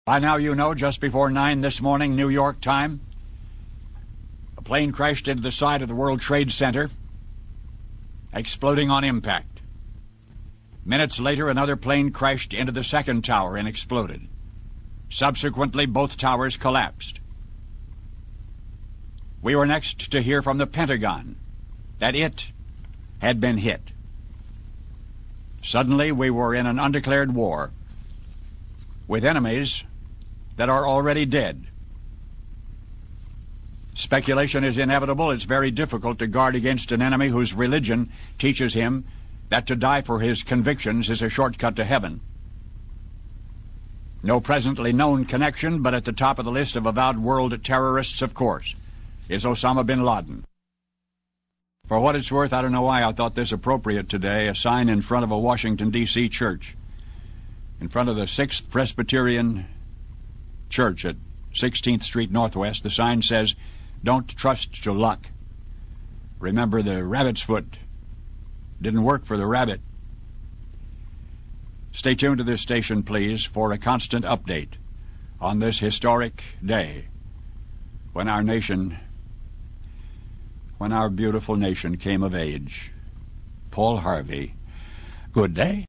Paul Harvey's evening comment on 11-22-63 (JFK Assassination)